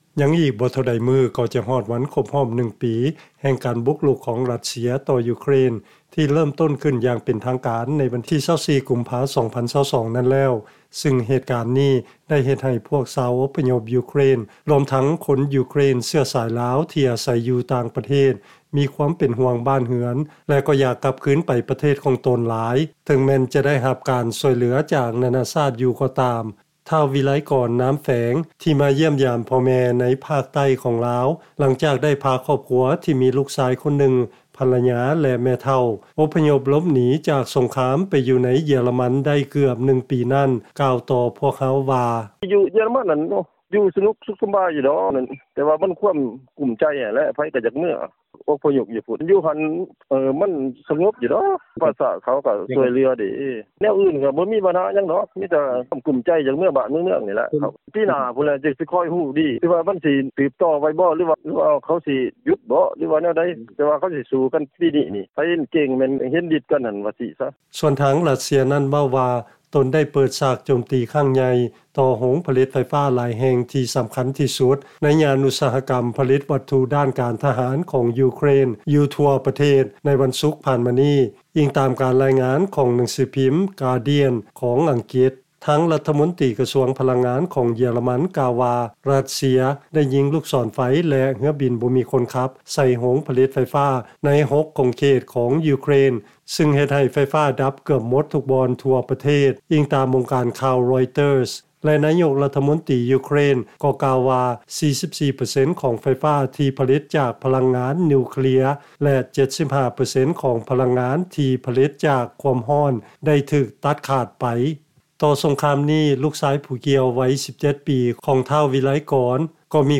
ຟັງລາຍງານ ຊາວອົບພະຍົບຢູເຄຣນ ທີ່ອາໄສຢູ່ຕ່າງປະເທດ ລວມທັງເຊື້ອສາຍລາວ ມີຄວາມເປັນຫ່ວງ ແລະມີຄວາມຫວັງໜ້ອຍຫຼາຍ ທີ່ຈະໄດ້ກັບໄປປະເທດ